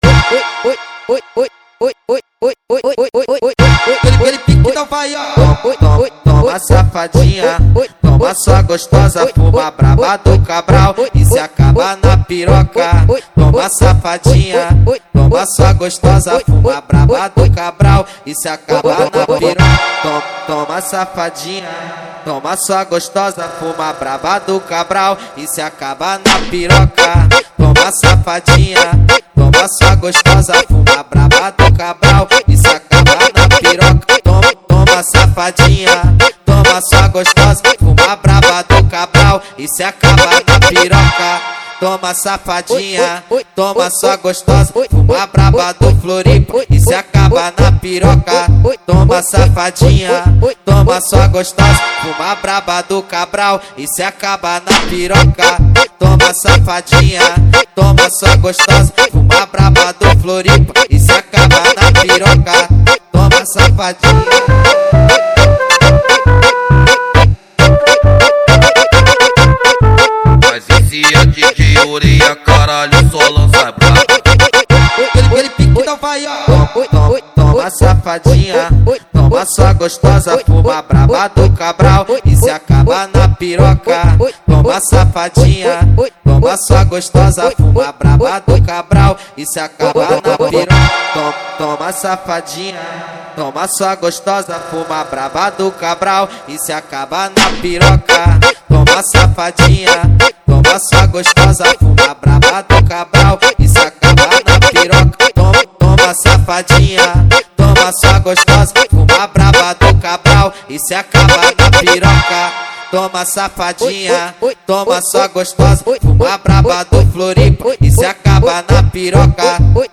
2024-07-31 14:29:52 Gênero: Funk Views